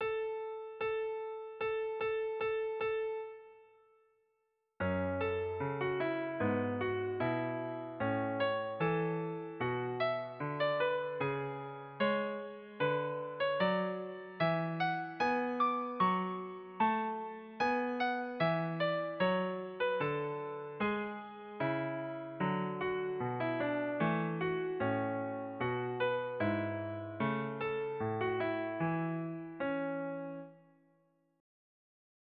Kleines Übungsstück 2 in A-Dur für Violine
Digitalpiano Casio CDP-130